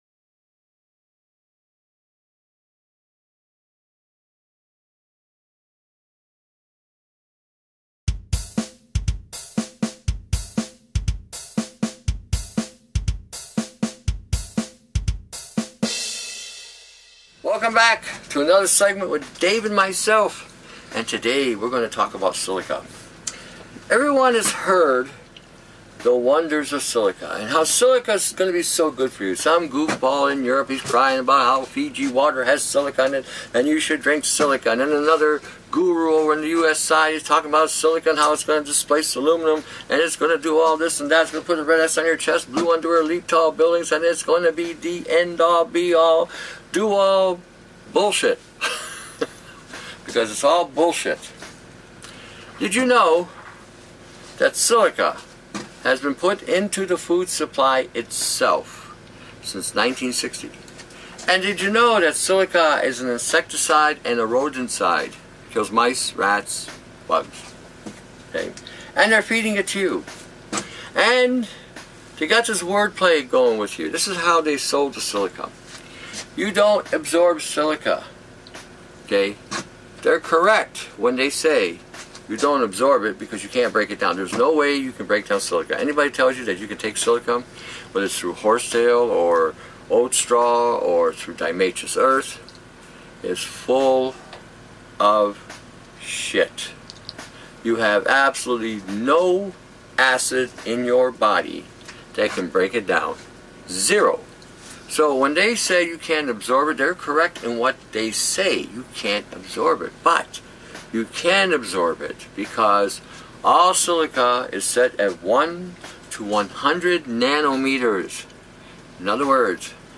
Herbalist warns against the dangers of silica and affects on the body